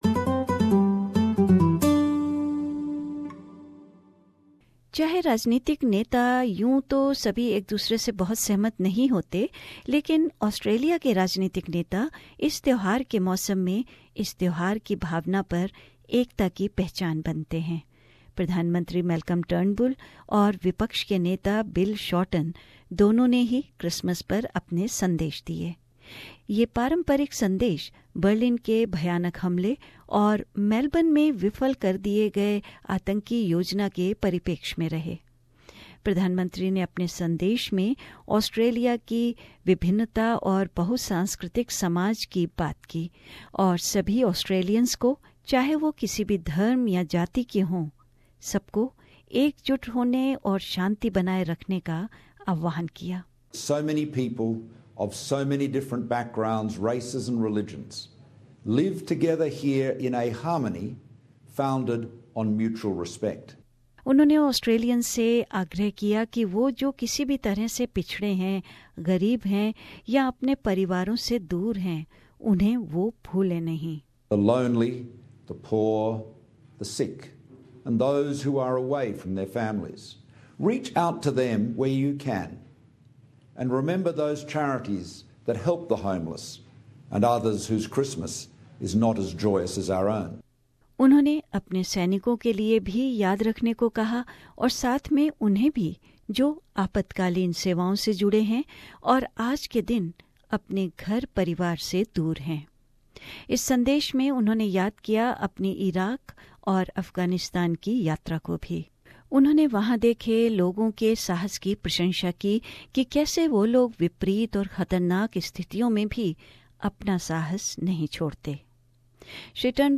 सुनिये यह फीचर ...